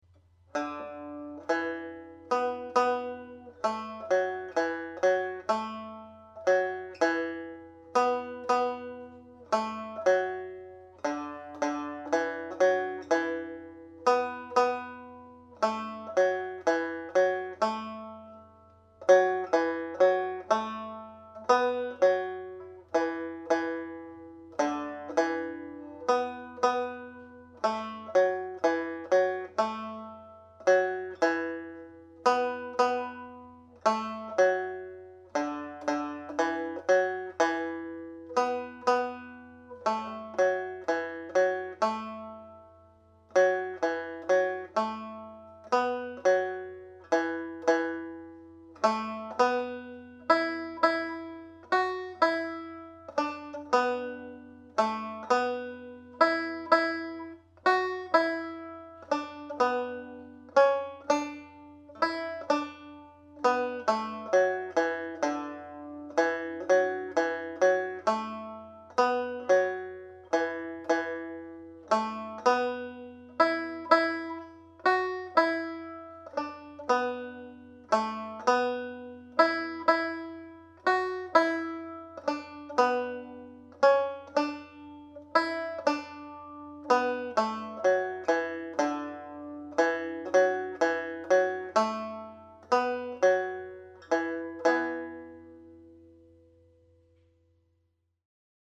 Slide (E Minor)
played slowly